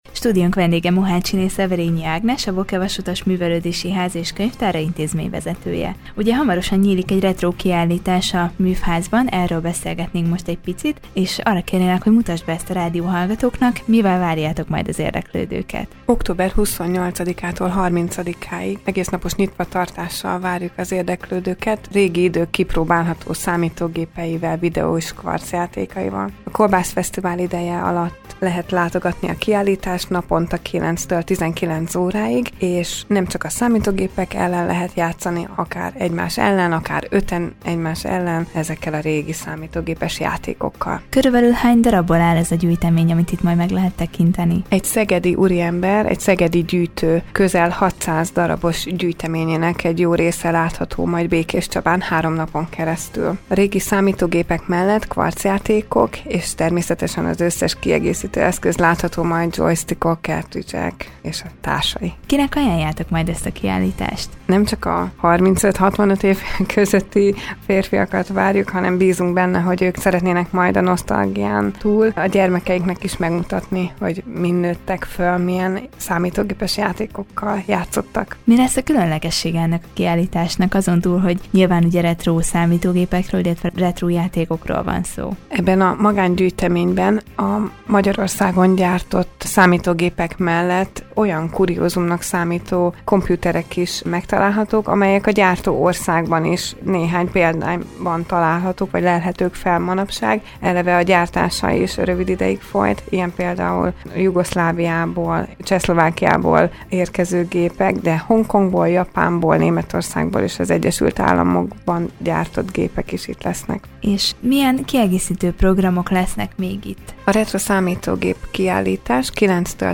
Vele beszélgetett tudósítónk a hétvégén megrendezésre kerülő Retro Számítógép, Videó- és Kvarcjáték Kiállításról.